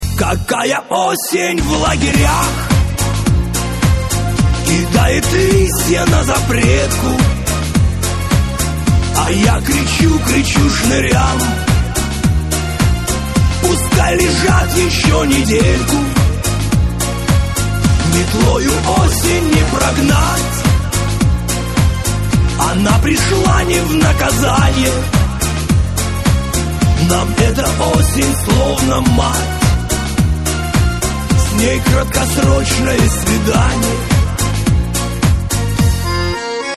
душевные
грустные
тюремные
Блатняк